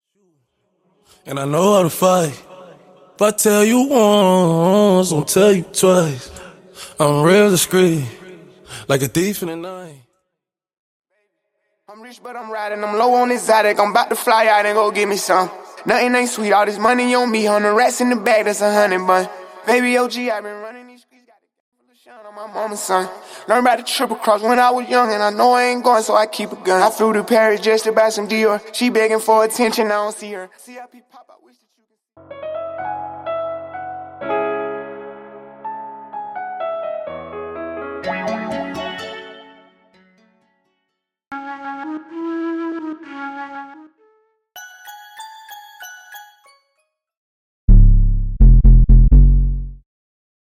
Studio All Bassline Stem
Studio Flutes, Piano & Winds Stem
Studio Keys, Strings & Adlibs Stem
Studio Percussion & Drums Stem